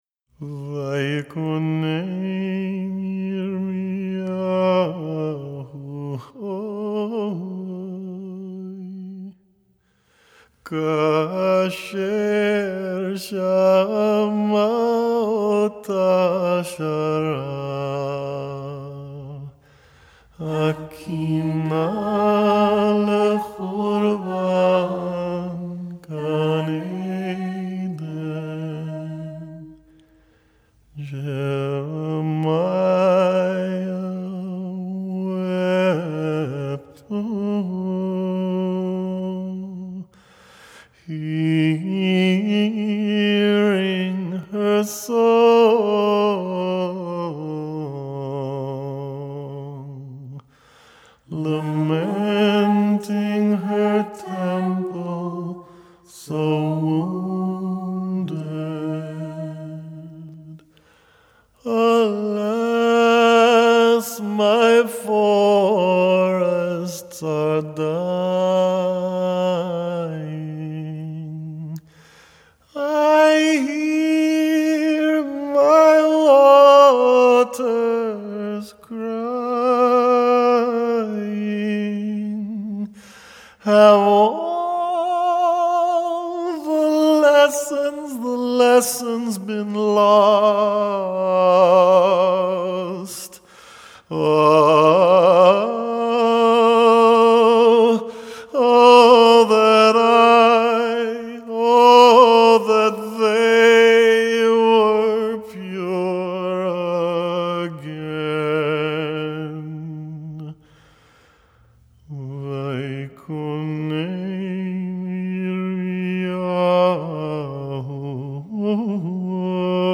plaintive voice